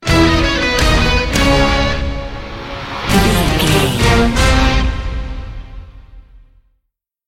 Ionian/Major
C#
horns
drums
electric guitar
synthesiser
orchestral
orchestral hybrid
dubstep
aggressive
energetic
intense
strings
synth effects
wobbles
driving drum beat
epic